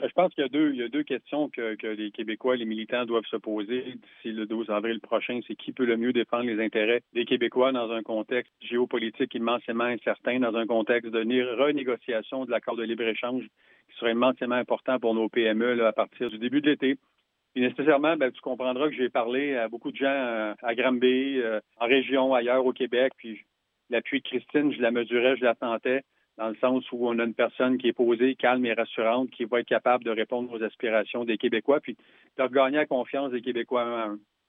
En entrevue avec la salle des nouvelles, il revient sur ce qui a motivé sa décision.